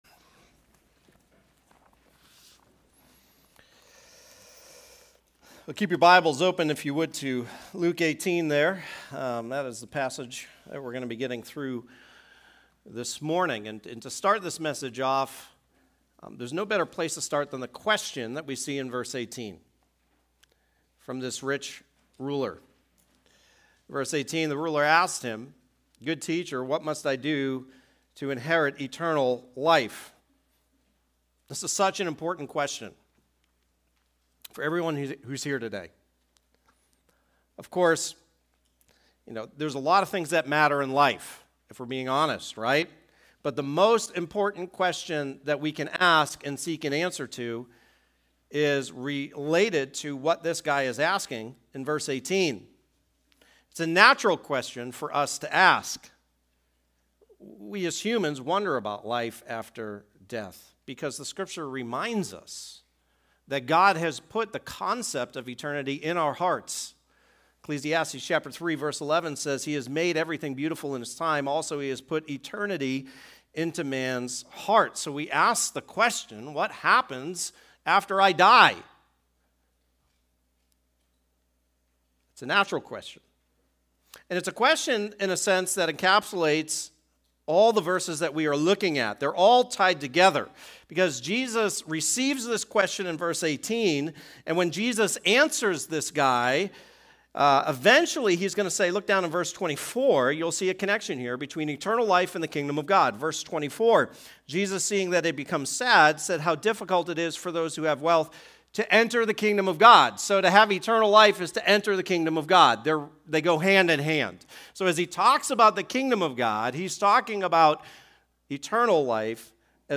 Sermons | Gospel Life Church